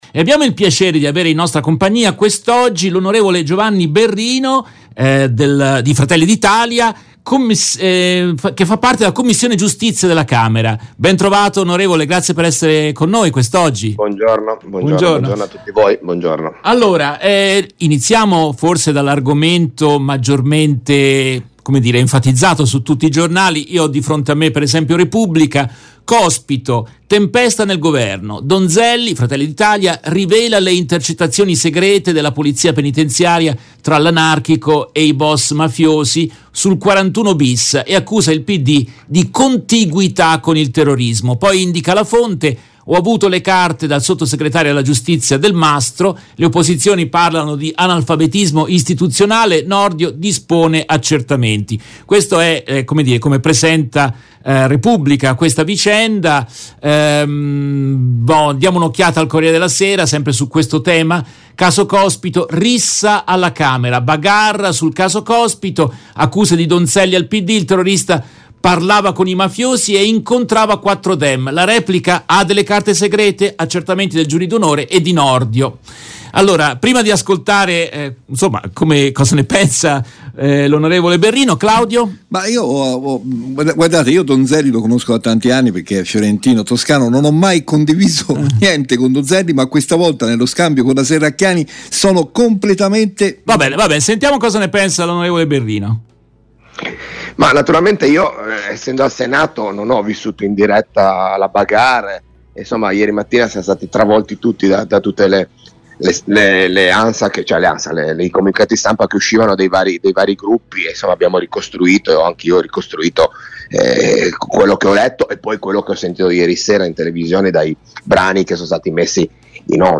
In questa trasmissione intervistano il senatore Giovanni Berrino, di FdI, membro della 2ª Commissione permanente (Giustizia) al Senato. Tra i temi toccati: rissa alla Camera sul caso Cospito; sull'invio delle armi pareri opposti del presidente italiano e di quello croato; la politica e il dilemma dei flussi migratori.